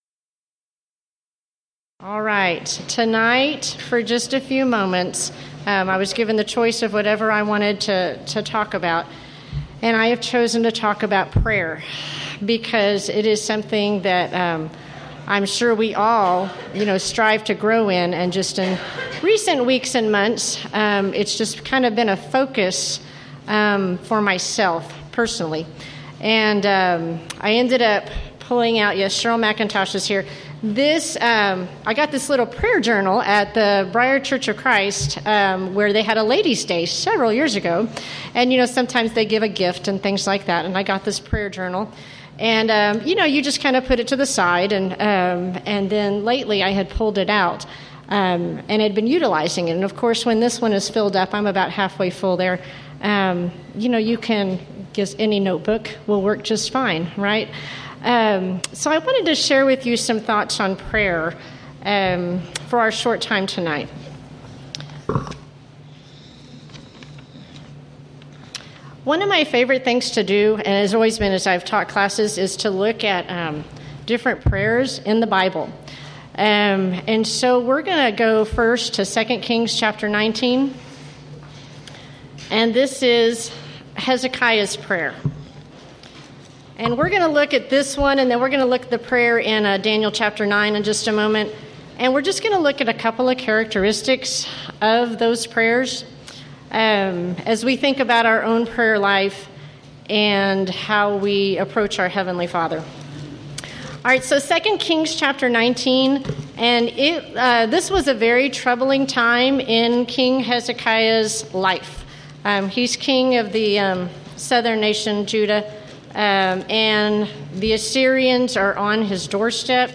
Title: Thursday Evening Devotional
Event: 9th Annual Texas Ladies in Christ Retreat Theme/Title: Studies in Parables